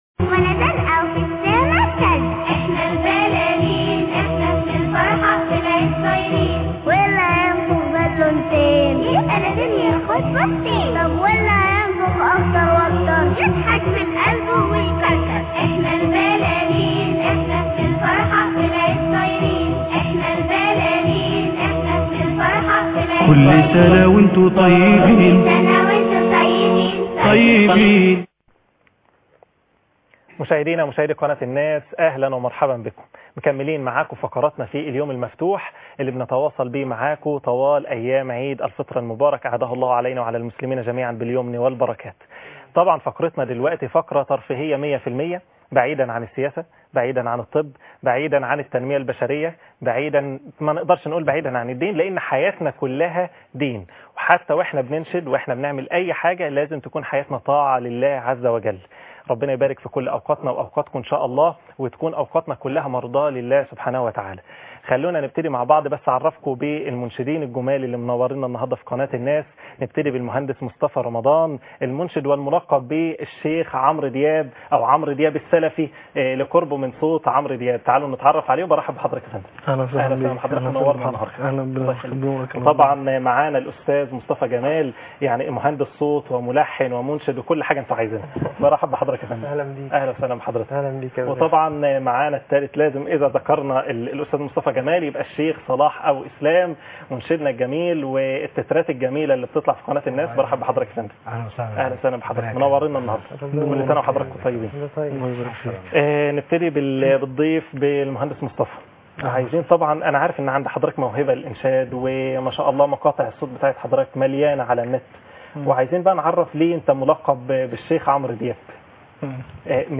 فقره انشاديه ( 18/8/2012.